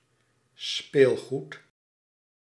Ääntäminen
IPA : /tɔɪ/ US : IPA : [tɔɪ]